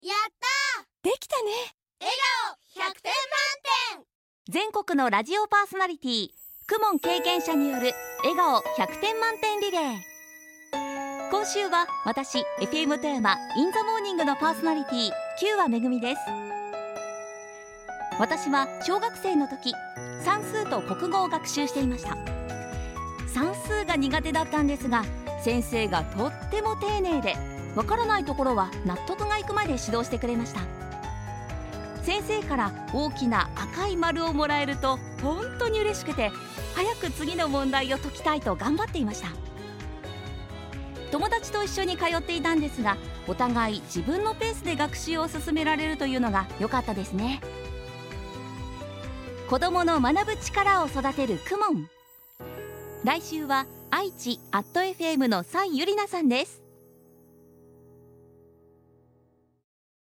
全国のパーソナリティの声